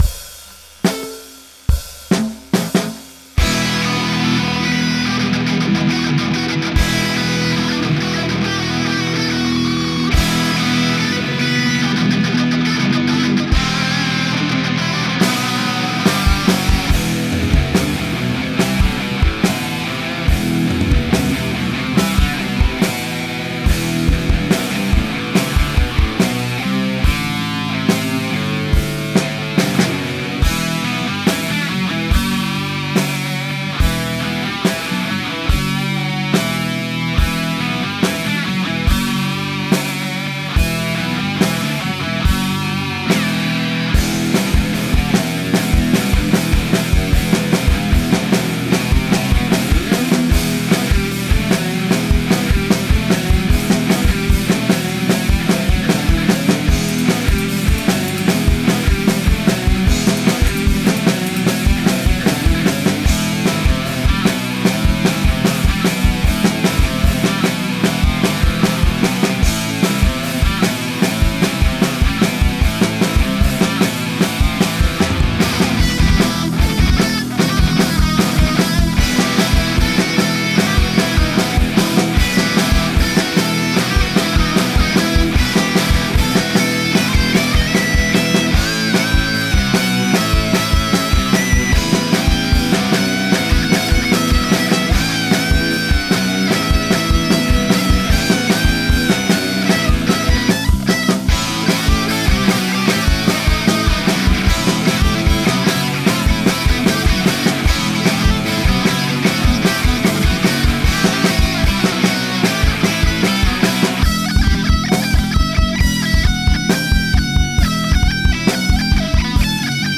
Sporty metal